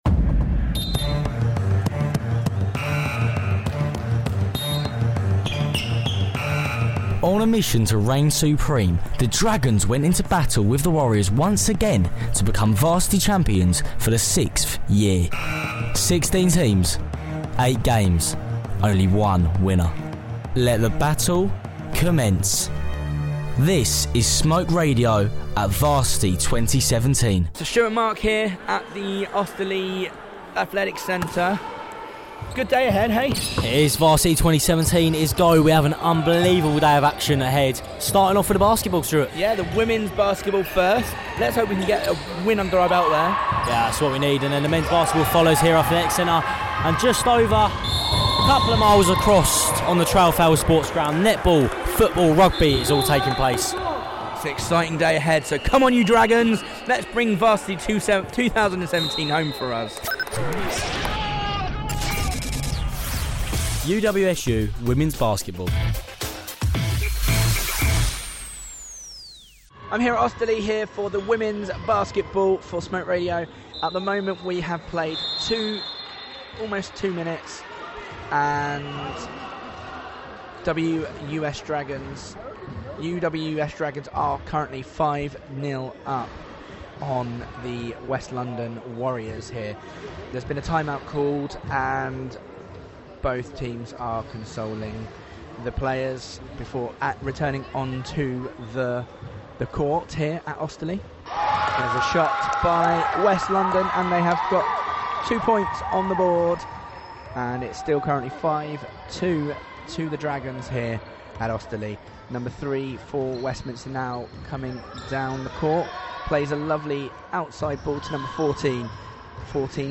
Foam fingers at the ready…Catch up on the Basketball action from Varsity 2017.